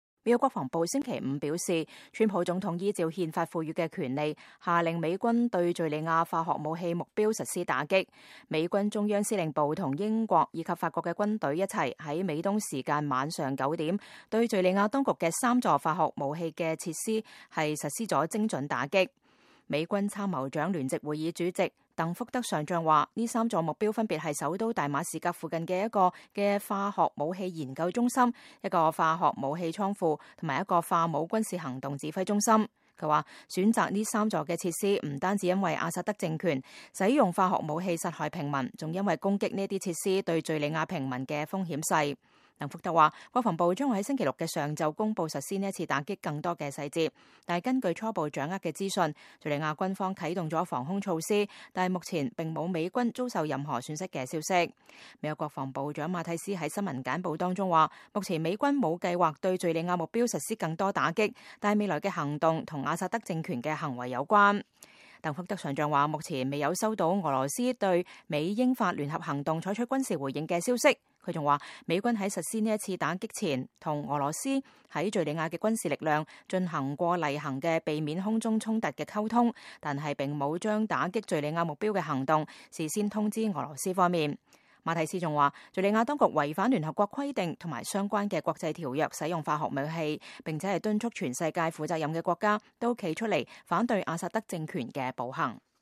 馬蒂斯部長與鄧福德上將2018年4月13日就打擊敘利亞化武目標做簡報（美國國防部）